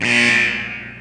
klaxon1.mp3